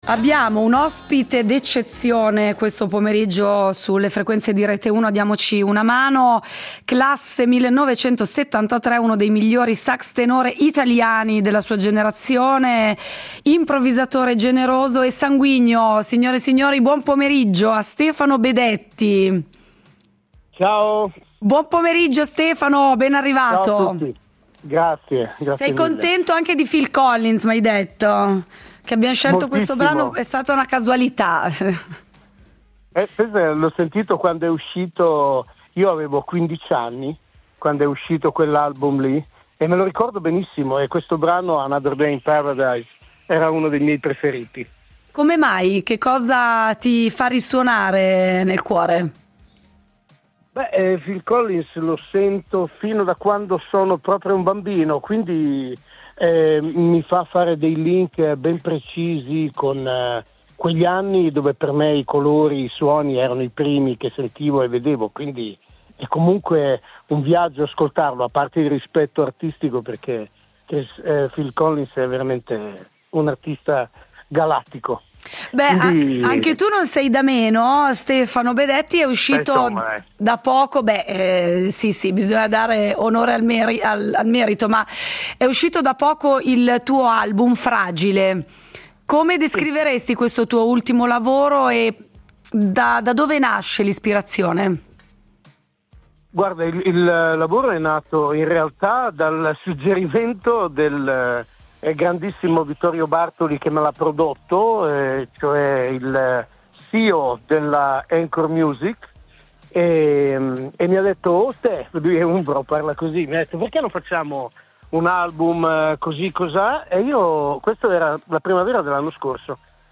in questa intervista si parlerà di musica